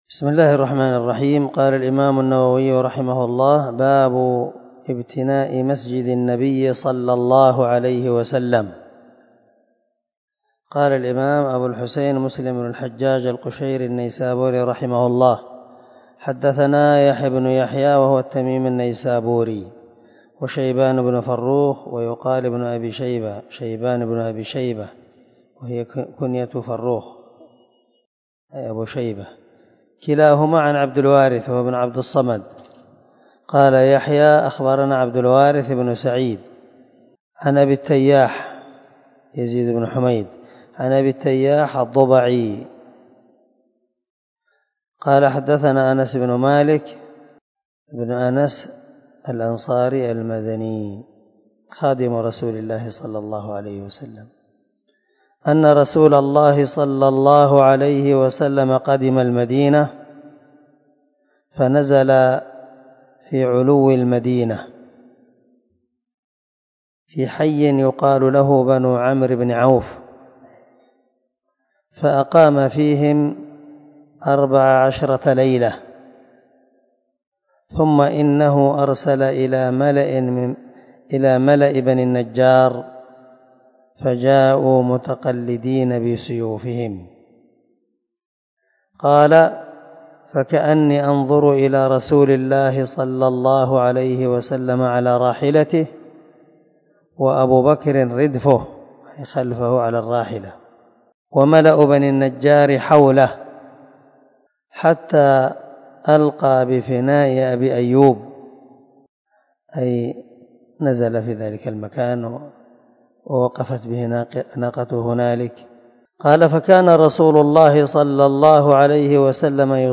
دار الحديث- المَحاوِلة- الصبيح